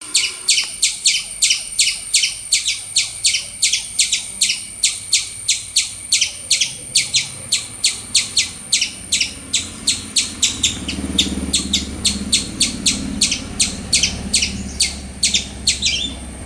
Chirping
These melodic, rhythmic vocalizations are often associated with positive emotions, such as contentment or excitement.
Chirping.mp3